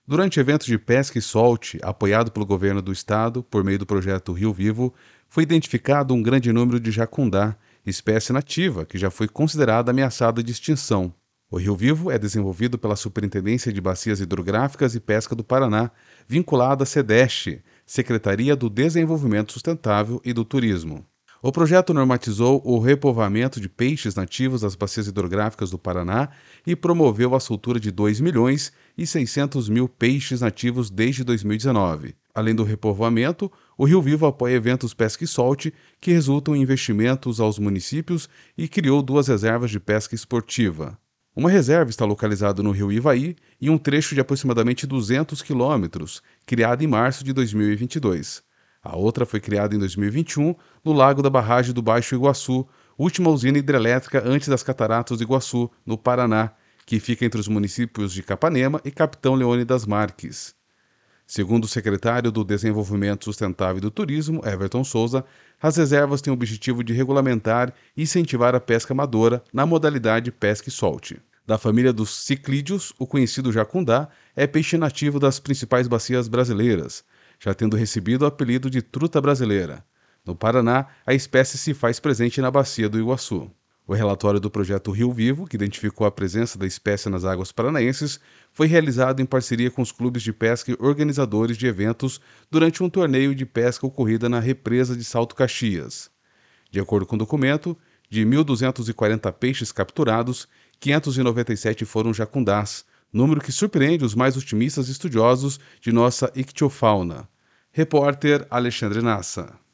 De acordo com o documento, de 1.240 peixes capturados, 597 foram jacundás, número que surpreende os mais otimistas estudiosos de nossa ictiofauna. (Repórter: